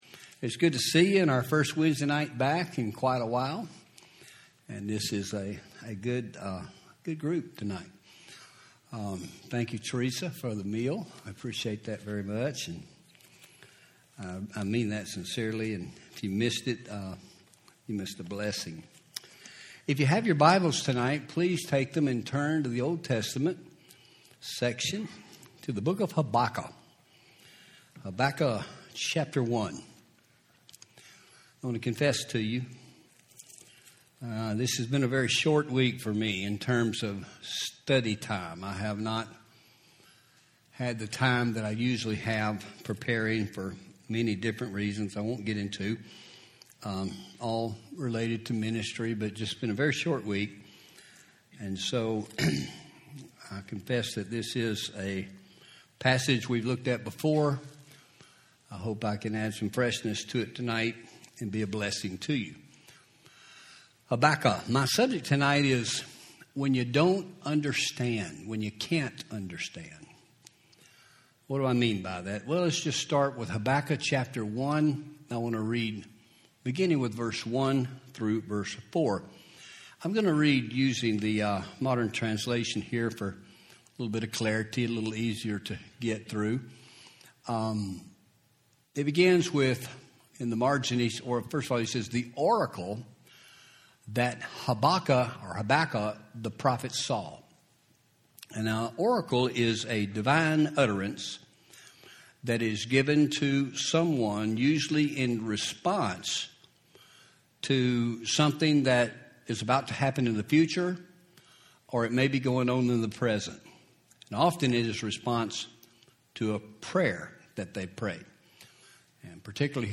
Home › Sermons › When You Can’t Understand